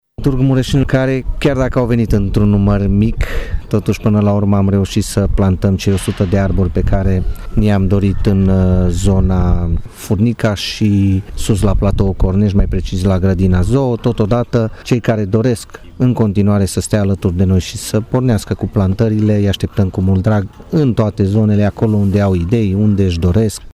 Viceprimarul Claudiu Maior a mulțumit angajaților firmelor care au participat la plantări, dar a remarcat că puțini târgumureșeni au răspuns invitației municipalității  de a se alătura acțiunii de plantare: